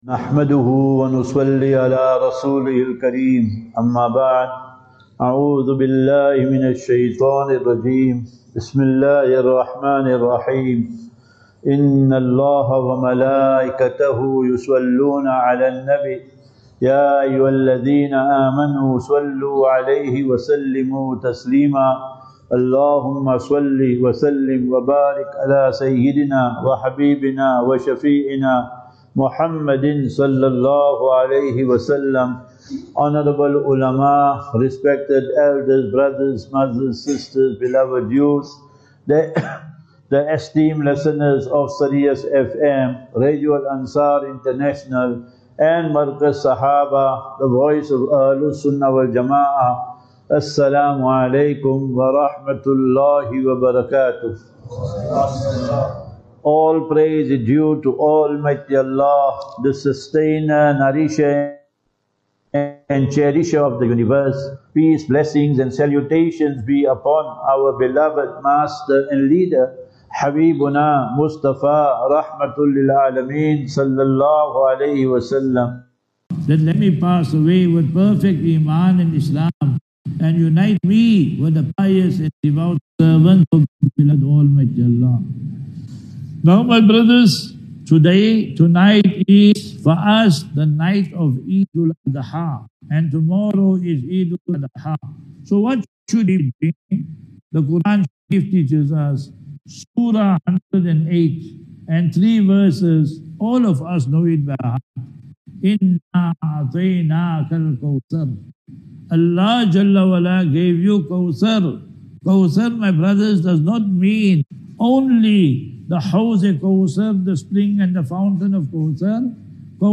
Lectures